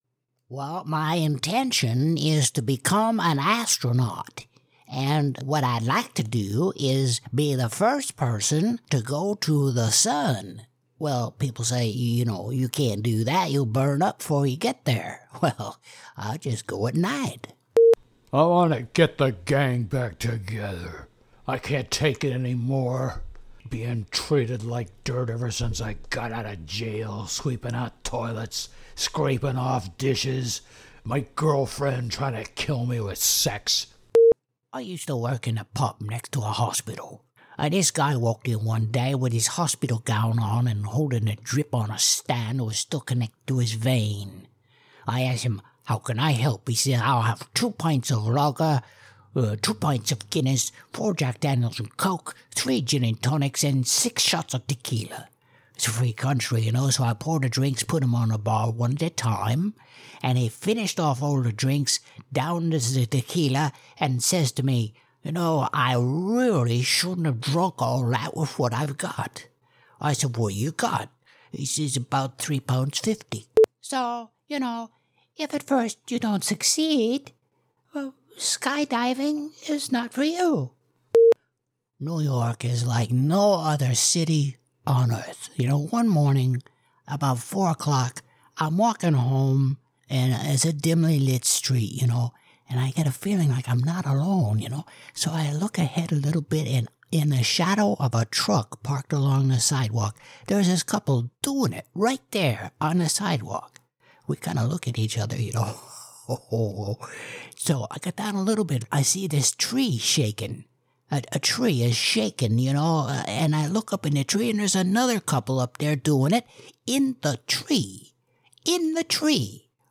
Character Voices Reel
Irish, American South
Middle Aged
Senior